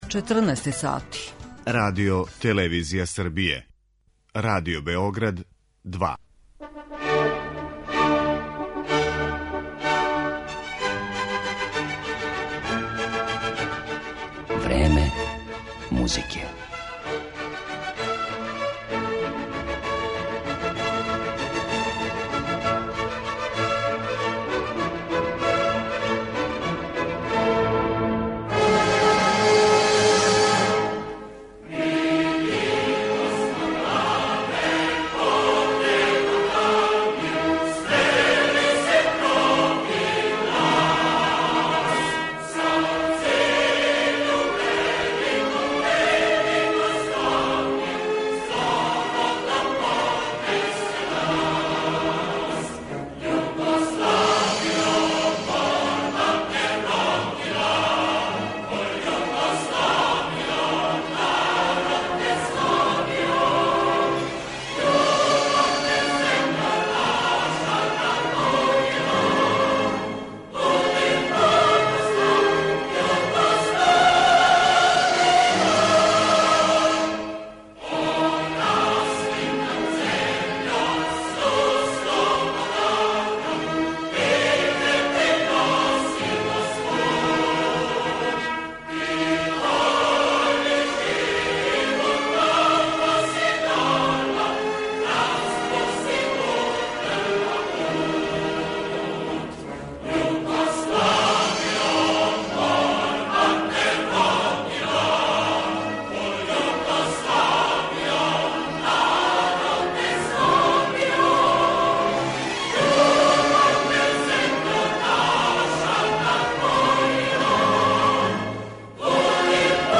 Овај скуп је одржан у САНУ крајем септембра 2015. године и окупио је преко 50 учесника пристиглих са разних страна - из Бугарске, Румуније, Мађарске, Словеније, Русије, Литваније, Немачке, Велике Британије, САД, Турске, Кипра, поред, наравно, наших музиколога. Осетљива веза музике и политике је, у сваком случају, била црвена нит свих излагања.